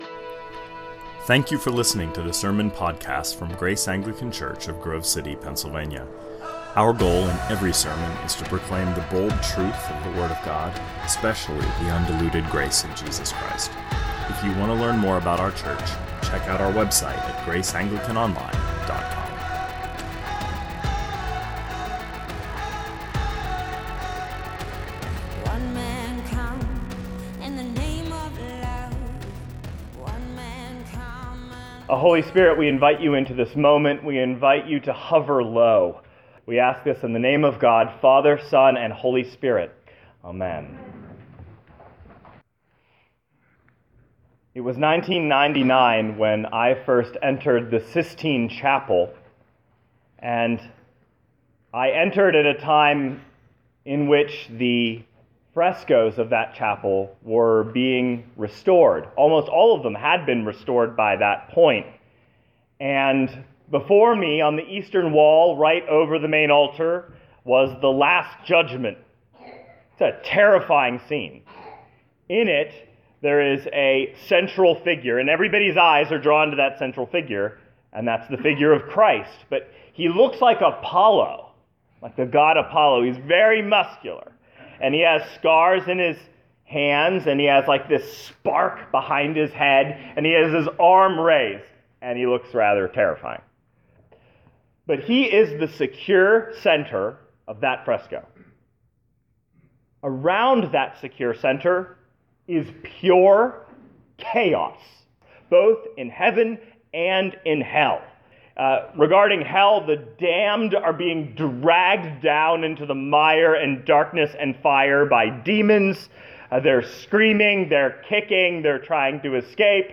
2019 Sermons The Center & the Chaos -An Easter Meditation Play Episode Pause Episode Mute/Unmute Episode Rewind 10 Seconds 1x Fast Forward 30 seconds 00:00 / 27:45 Subscribe Share RSS Feed Share Link Embed